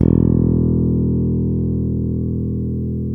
Index of /90_sSampleCDs/East Collexion - Bass S3000/Partition A/FRETLESS-A